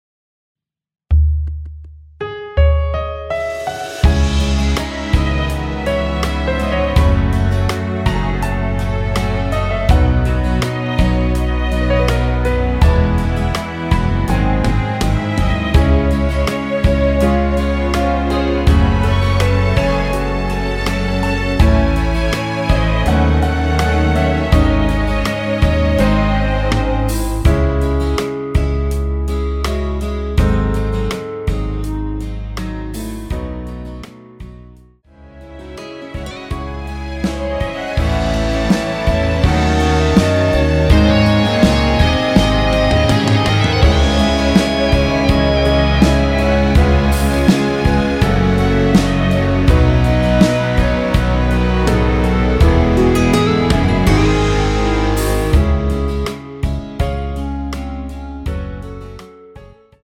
멜로디 MR입니다.
원키에서(-1)내린 멜로디 포함된 MR입니다.
?C#m
앞부분30초, 뒷부분30초씩 편집해서 올려 드리고 있습니다.